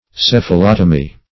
Search Result for " cephalotomy" : The Collaborative International Dictionary of English v.0.48: Cephalotomy \Ceph`a*lot"o*my\, n. 1.